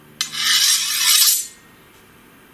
近战格斗的声音 " 拔剑出鞘
描述：击剑
标签： 脱护套 出鞘 unsheath 武士刀 武器 金属 战斗 刀片
声道立体声